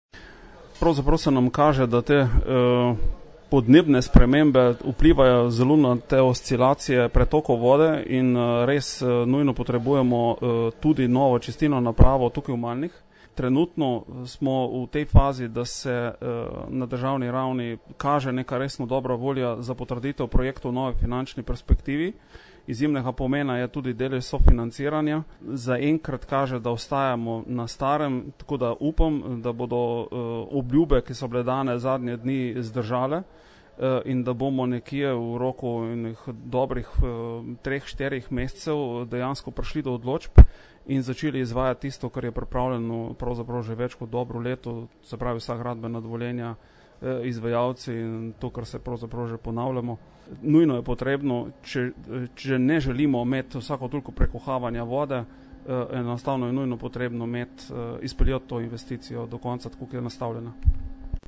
IZJAVA ROBERT SMRDELJ